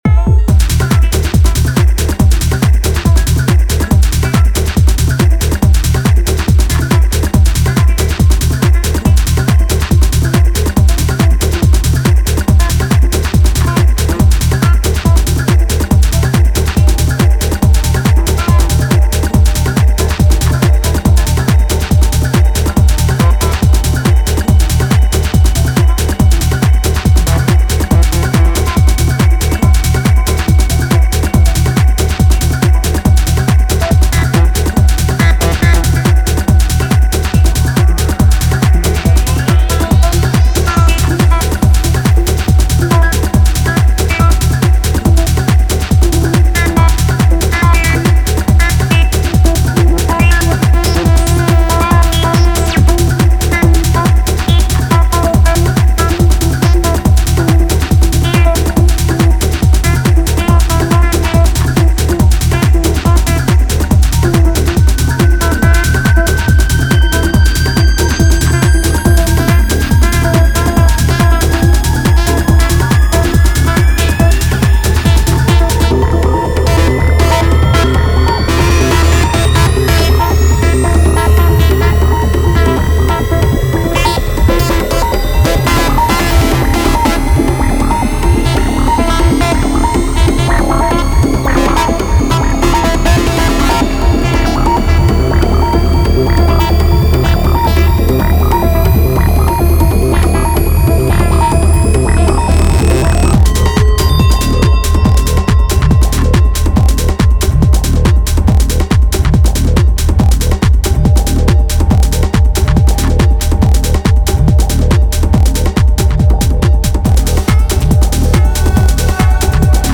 a collection of 4 tracks fine-tuned for the chaotic club.